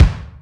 Steel Kick Drum Sample G# Key 69.wav
Royality free kick drum sound tuned to the G# note. Loudest frequency: 292Hz
steel-kick-drum-sample-g-sharp-key-69-2WG.ogg